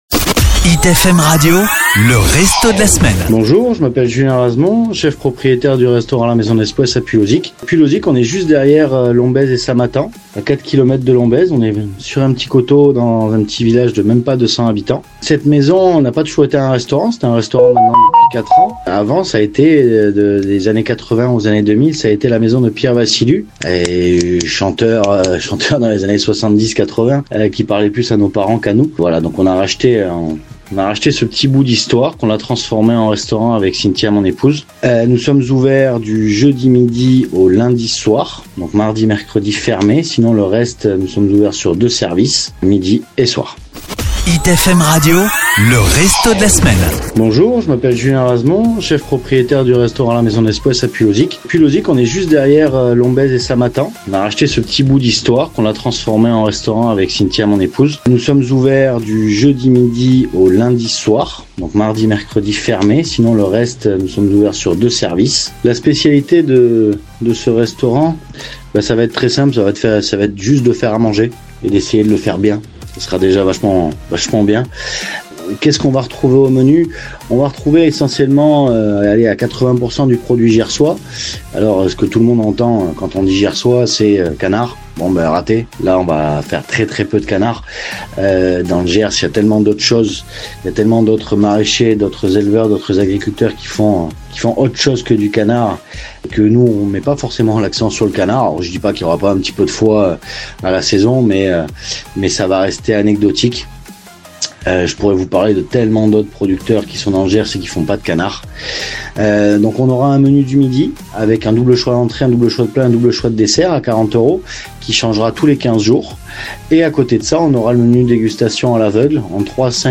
Lors de cet entretien, le chef nous dévoile également une petite anecdote qui lui a mis du baume au coeur, offrant ainsi un aperçu chaleureux et humain de son parcours.